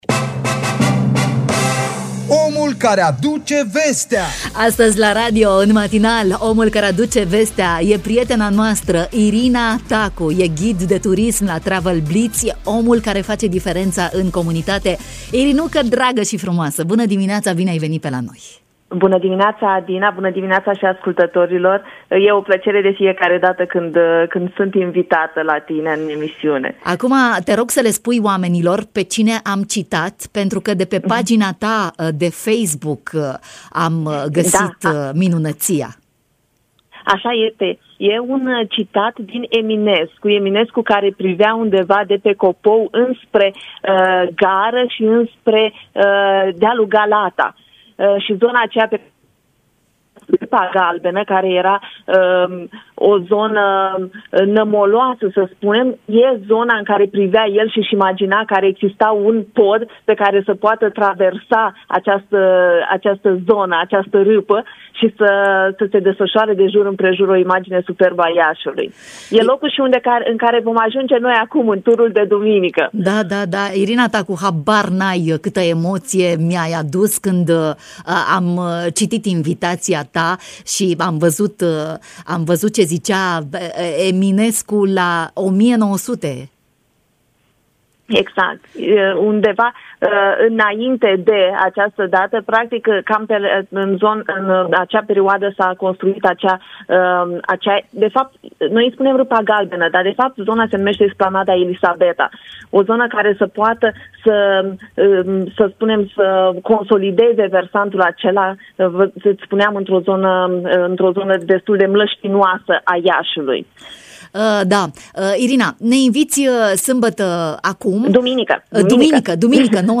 Prima pagină » Emisiuni » Bună dimineaţa » Hai la o plimbare ghidată pe ulițele Iașului!